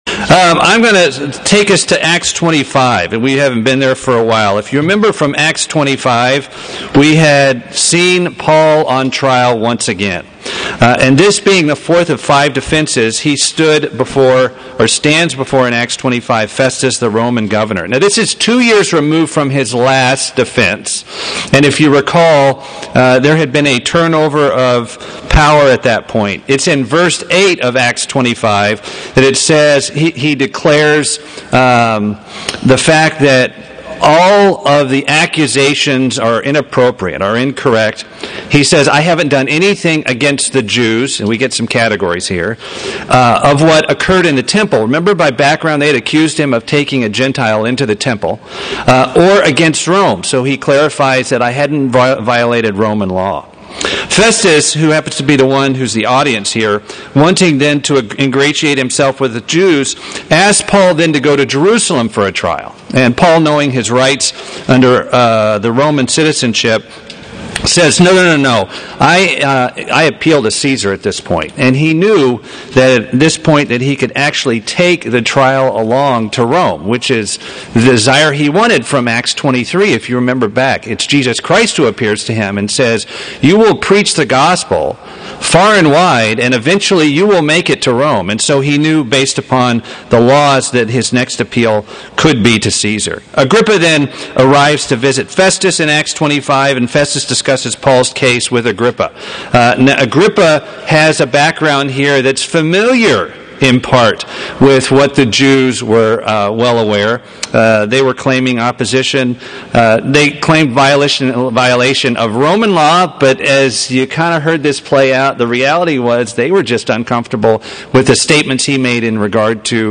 Paul Conversión Atonement Ship wreck Prison epistles sermon book of Acts Studying the bible?